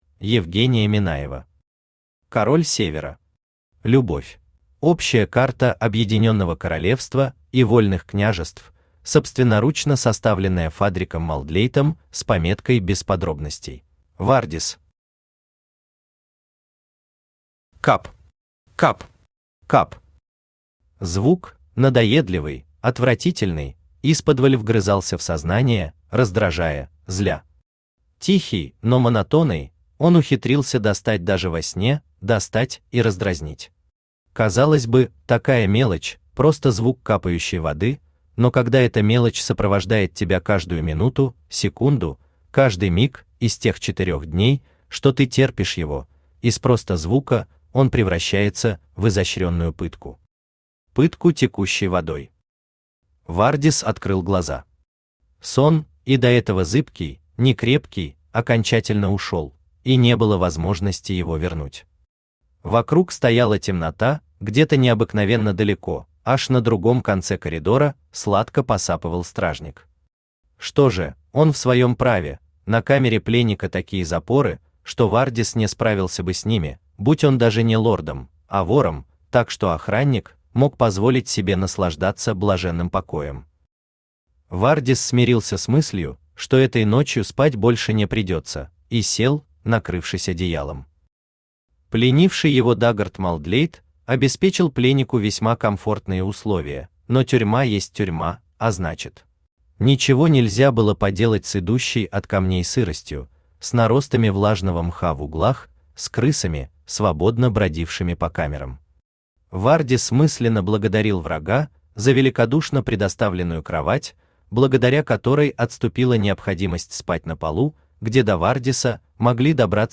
Любовь Автор Евгения Минаева Читает аудиокнигу Авточтец ЛитРес.